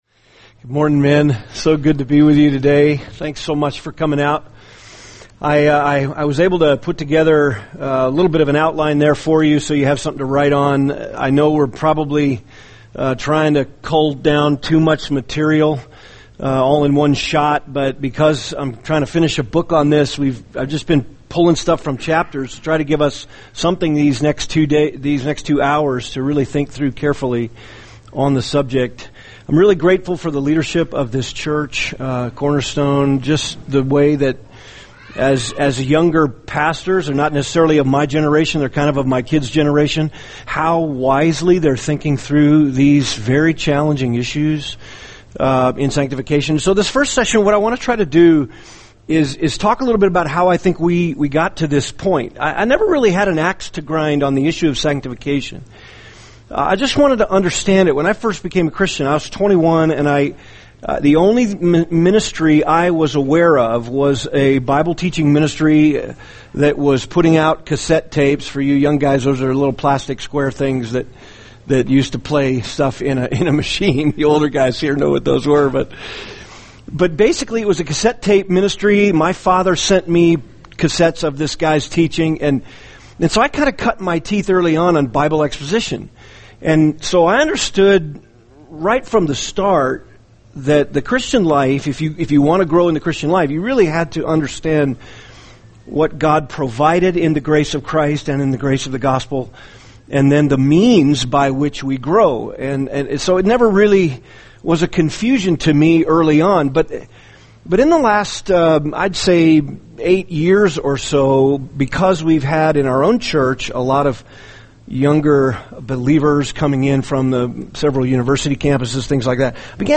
[seminar] Trust & Obey: Faith, Feelings, and Spiritual Growth (1 – How We Got Here) | Cornerstone Church - Jackson Hole